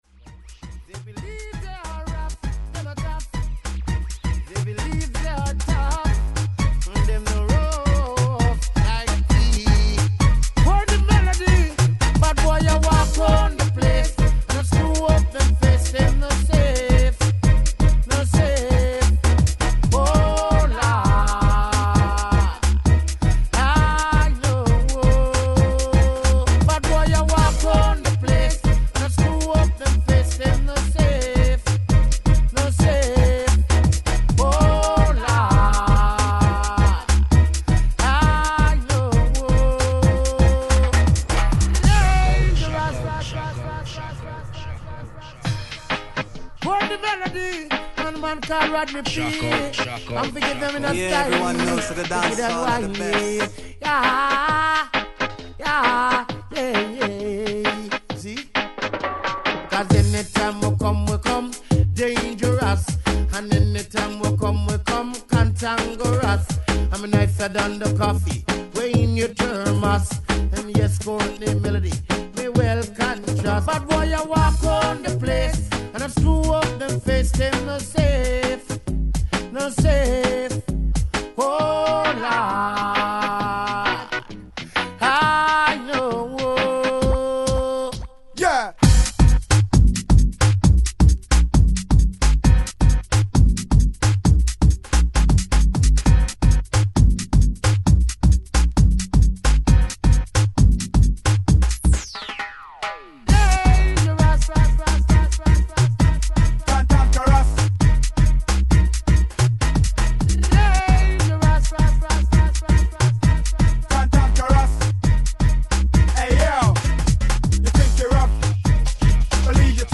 Some goodies recorded in Brussels
Harmonica